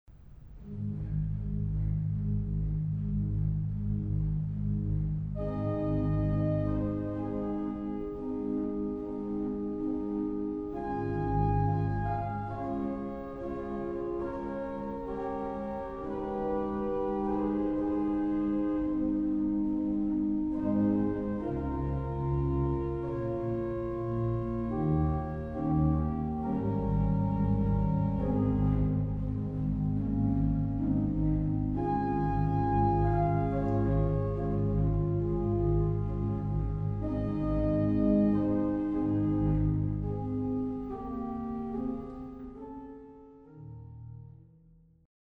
Dialogue des trois plans (Grand-orgue, Positif, Echo) avec deux « niveaux » de Récit : Grand-orgue accompagné au Positif, Positif accompagné à l’Echo.
Positif : Montre 8, Bourdon 8, 1ère et 2e Flûte 8
G.O. : Bourdon-Flûte 8, Flûte 8
Echo : Flûte 8, Bourdon 8
Pédale : Soubasse 16, Flûte 8, Flûte 4